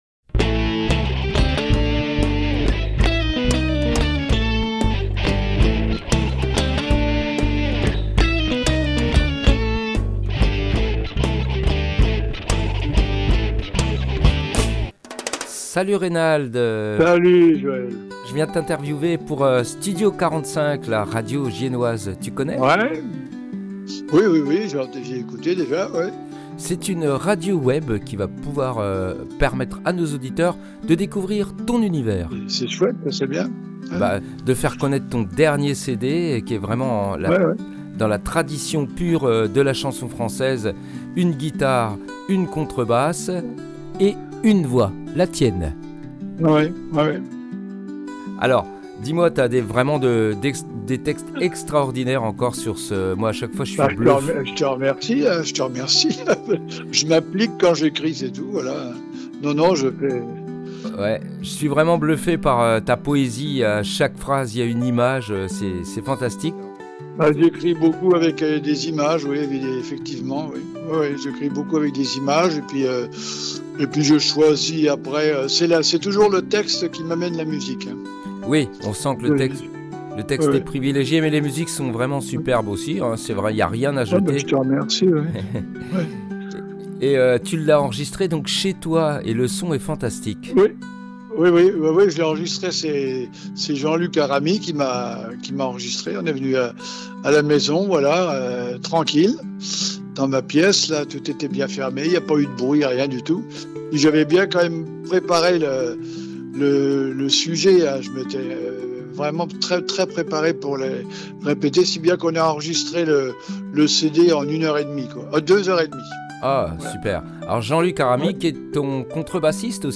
Une rencontre sensible et authentique au fil des mots et des notes, entre confidences et extraits de ses plus belles compositions.